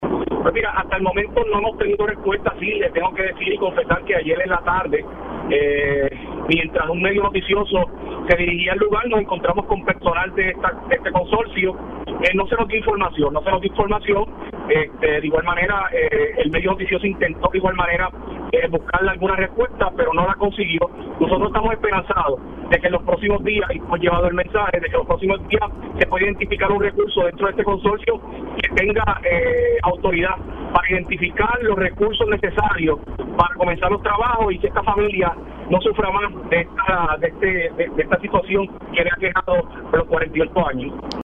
511-JOSEAN-GONZALEZ-ALC-PEnUELAS-LUMA-ENERGY-NO-HA-RESPONDIDO-PARA-CONECTAR-A-PAREJA-SIN-LUZ-POR-48-AnOS.mp3